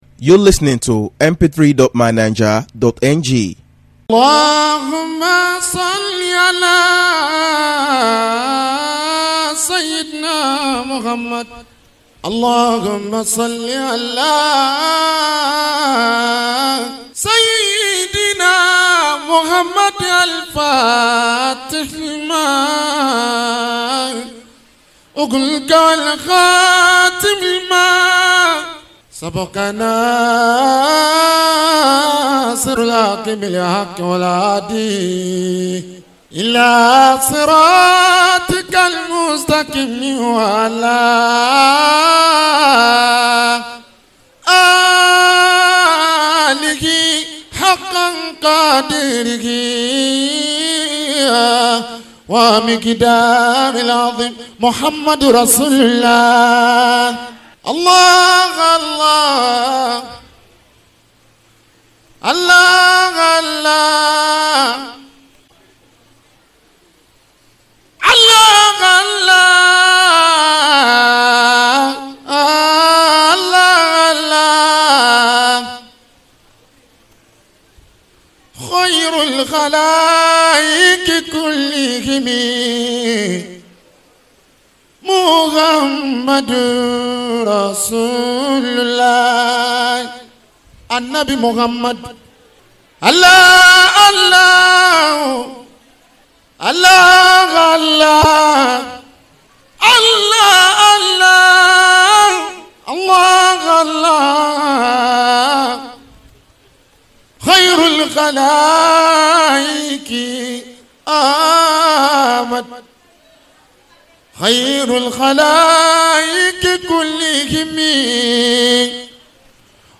At Al-Huda and Muhammad Rosulullah Global Family Solatu Zatia Day
PRAISE & WORSHIP